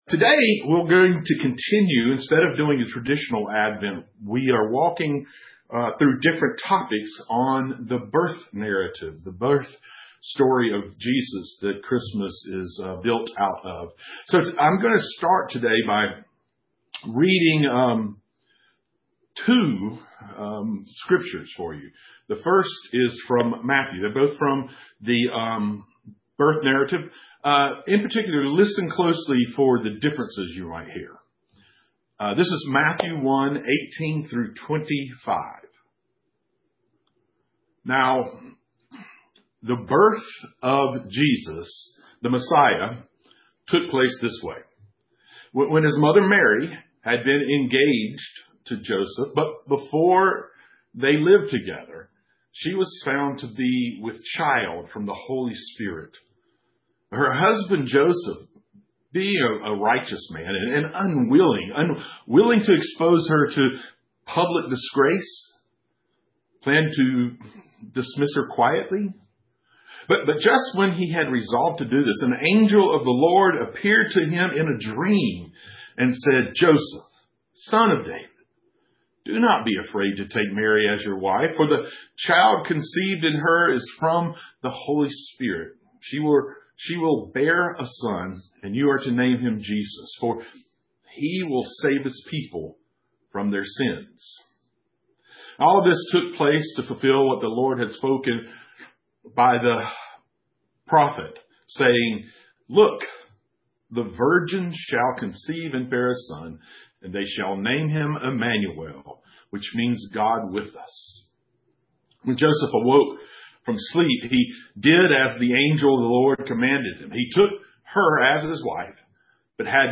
Matthew 1:18-25 and Luke 2:1-7 (streamed via Zoom and Facebook)